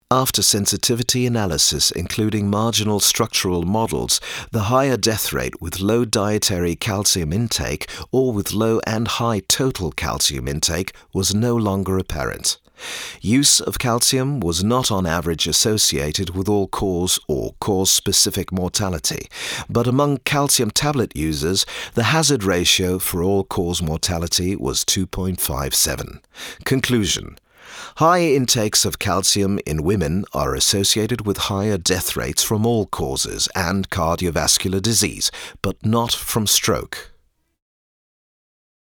mid-atlantic
Sprechprobe: eLearning (Muttersprache):
The warm but serious tone of his voice lends itself to many situations, where an American is required to speak in a " European" context.